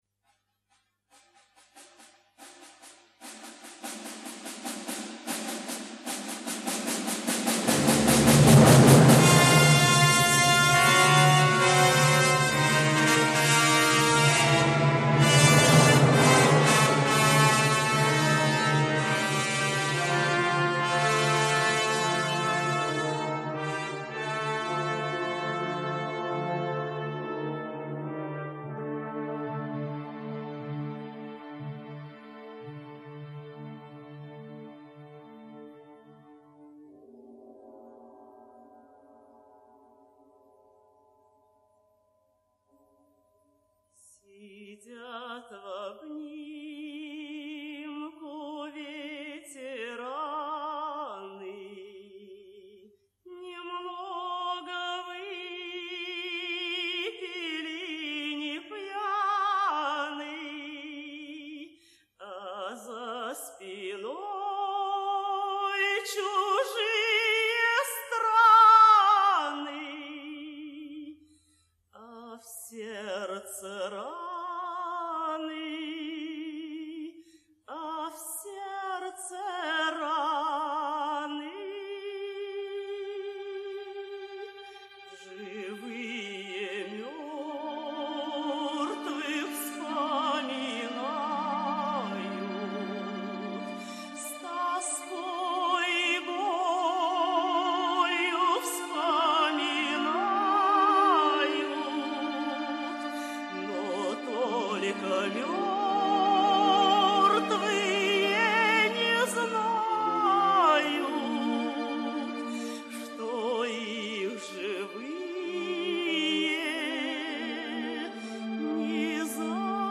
Военные песни